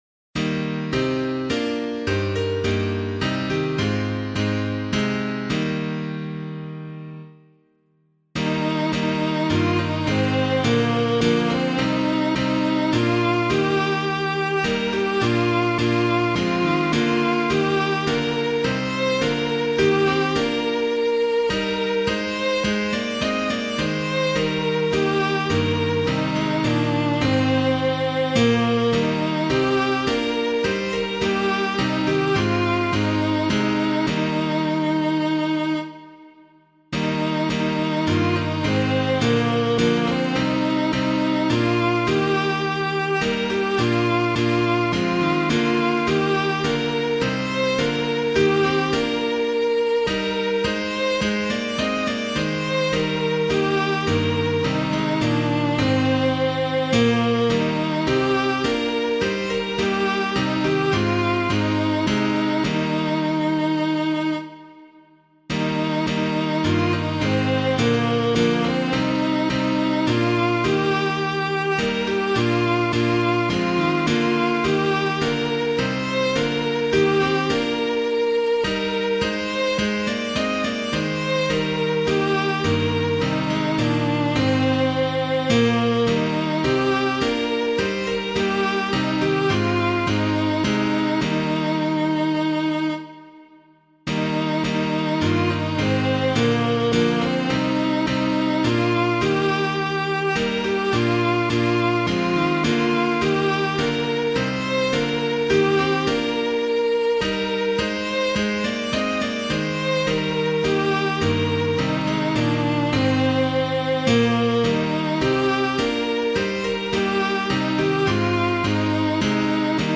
Composer:    traditional Irish melody; adapted from The Church Hymnary, 1927;
piano
Be Thou My Vision O Lord of My Heart [Hull - SLANE] - piano [alt].mp3